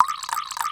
77 WATER.wav